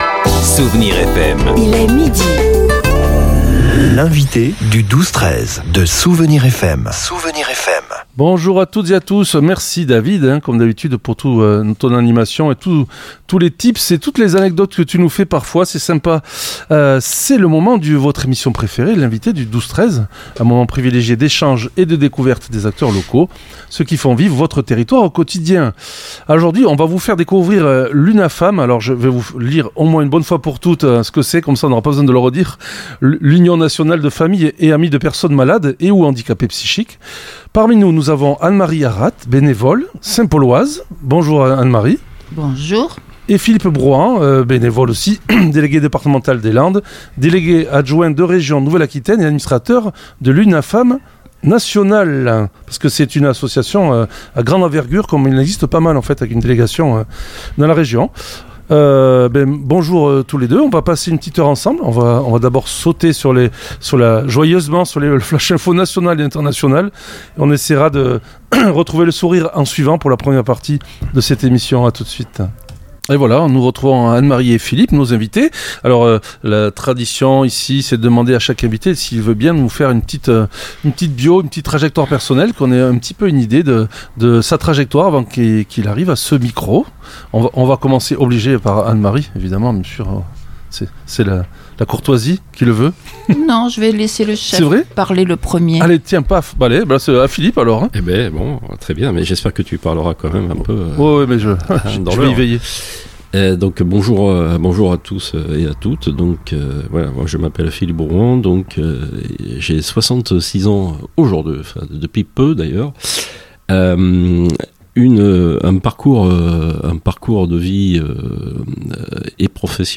L’invité(e) du 12-13 recevait aujourd’hui les représentants de l’UNAFAM des Landes